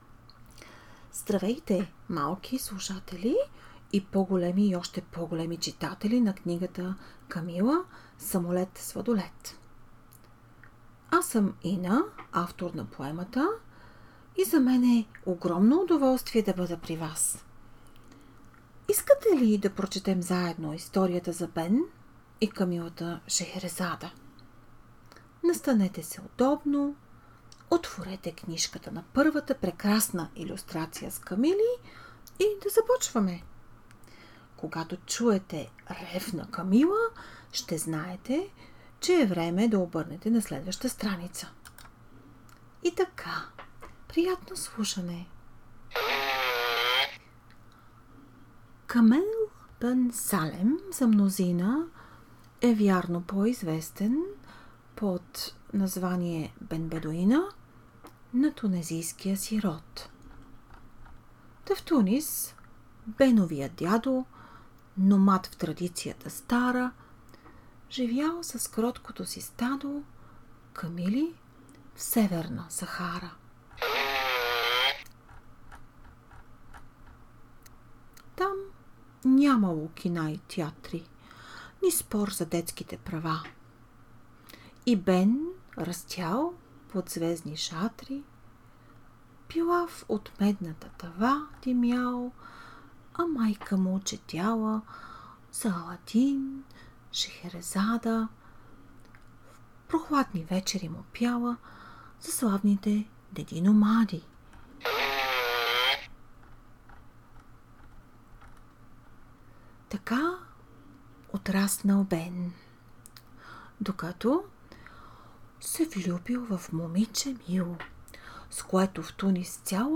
Ревяща камила ще им подскаже кога да обърнат на следващата страница.
Напевните рими и мелодиката на стиха искат да приласкаят  и целунат за лека нощ, а защо пък не и за добро утро.